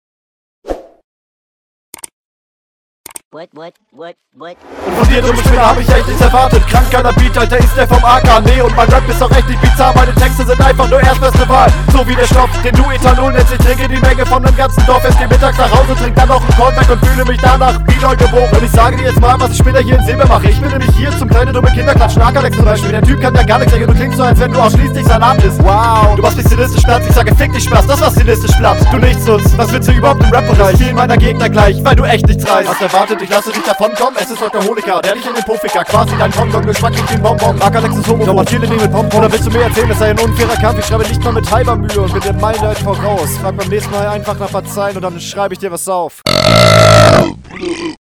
Asynchron geworden. Soundquali war erste Runde besser.
Irgendwie klingt der Flow total gehetzt und völlig unsicher auf dem …